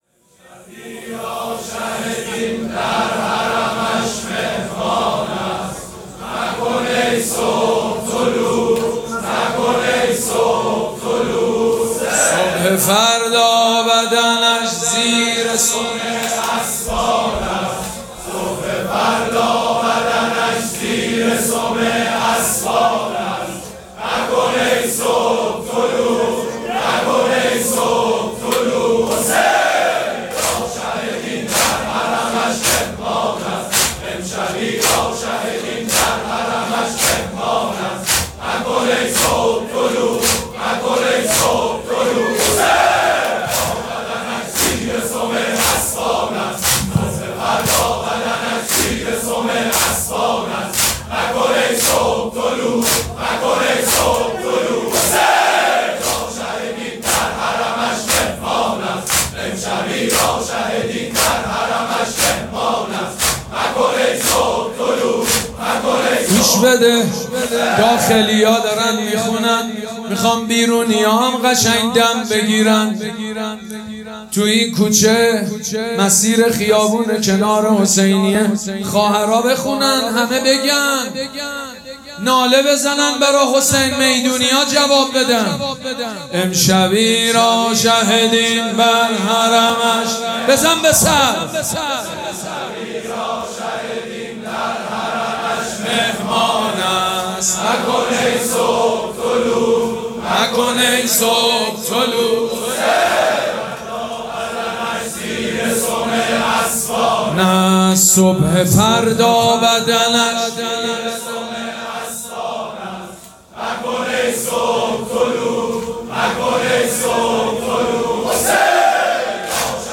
مراسم عزاداری شب دهم محرم الحرام ۱۴۴۷
دودمه
مداح
حاج سید مجید بنی فاطمه